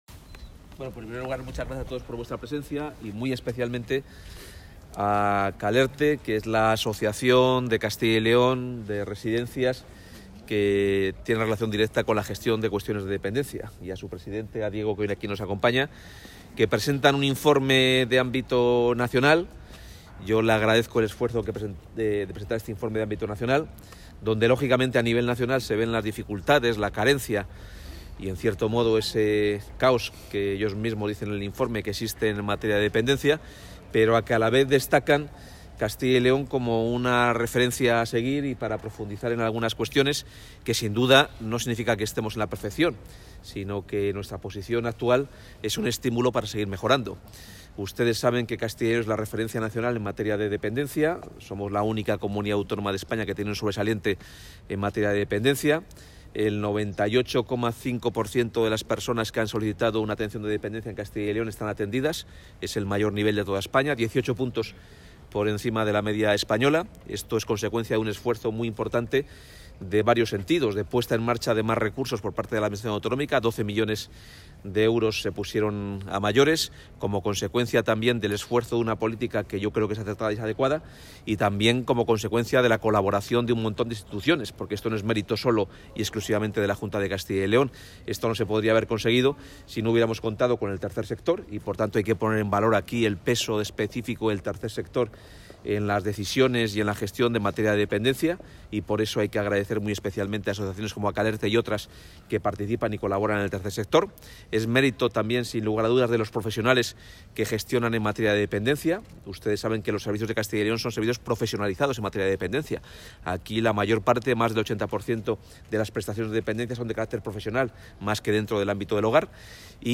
El consejero de Empleo, Carlos Fernández Carriedo, ha participado en la apertura de la jornada organizada por la Federación empresarial...
Declaraciones del consejero Carlos Fdez. Carriedo.